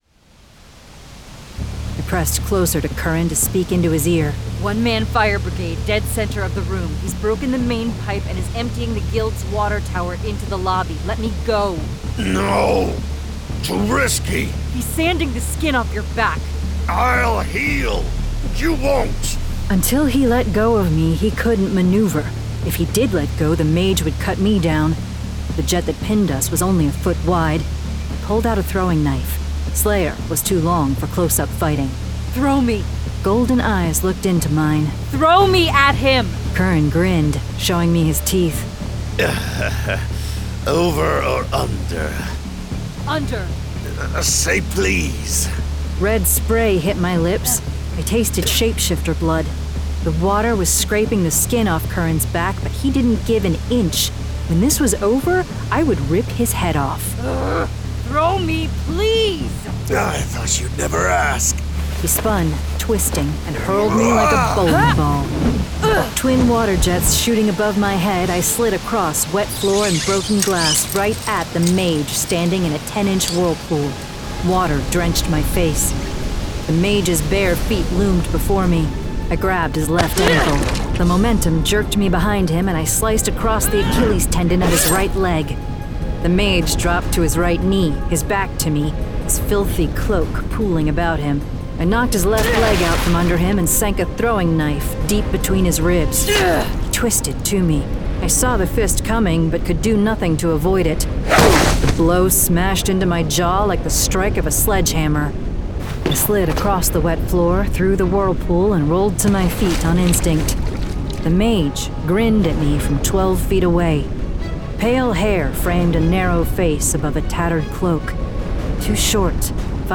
Full Cast. Cinematic Music. Sound Effects.
Genre: Urban Fantasy
This dramatized audiobook also includes 3 short stories: Naked Dinner, Conclave and Awake.